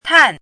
chinese-voice - 汉字语音库
tan4.mp3